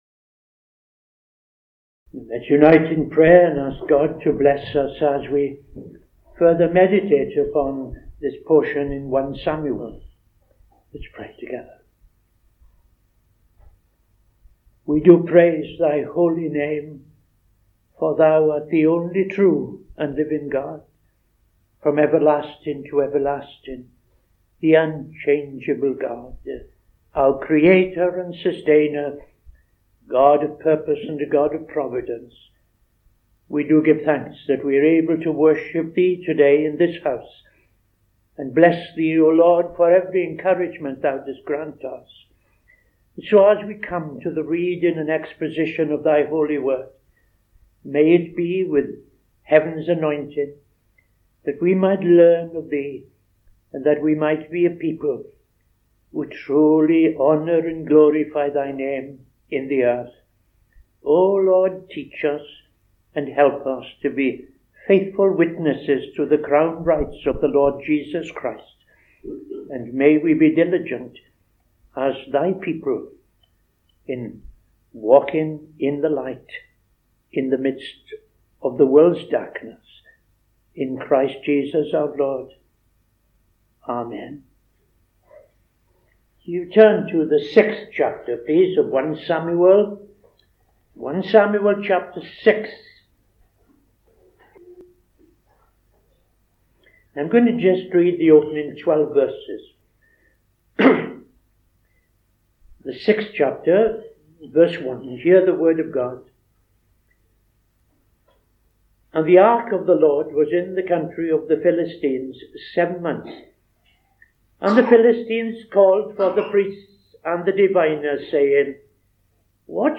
Bible Study - TFCChurch